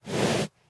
horse_breathe1.ogg